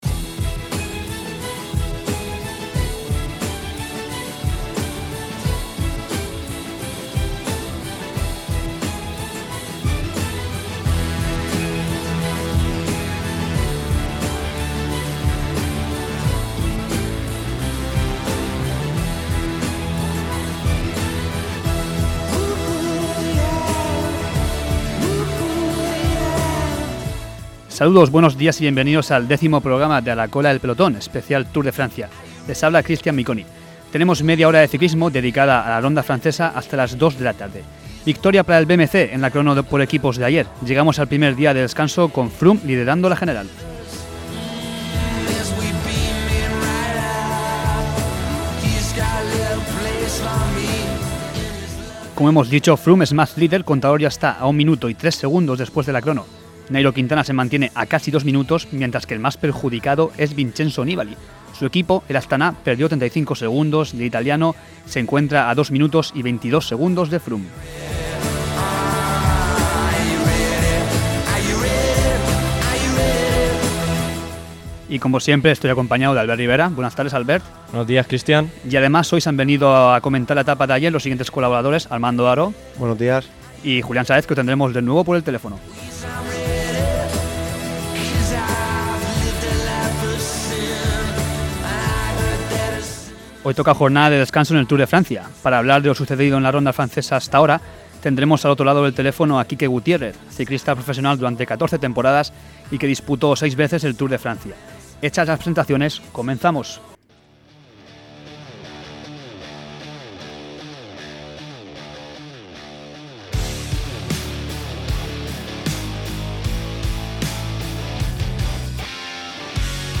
Programa diario sobre el Tour de Francia en Radio UMH. Con el análisis de la etapa del día.